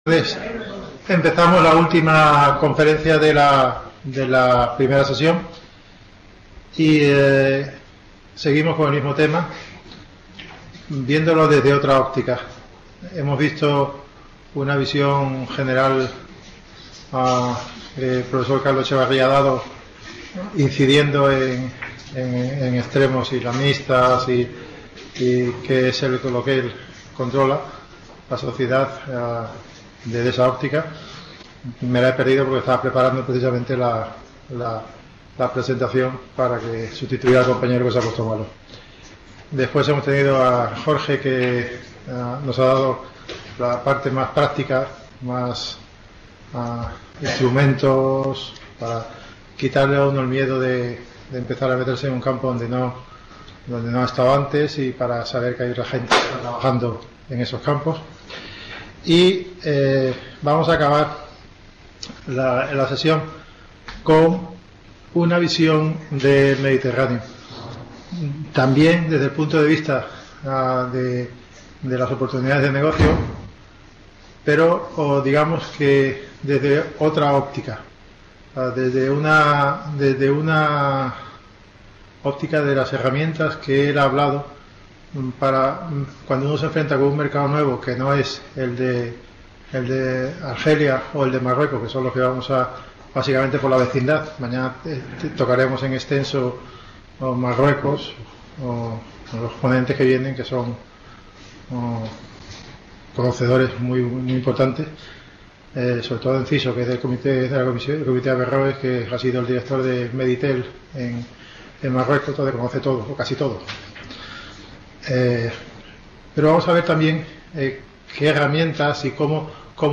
Investigador Asociado del Instituto Complutense de Estudios Internacionales (ICEI) | Red: UNED | Centro: UNED | Asig: Reunion, debate, coloquio...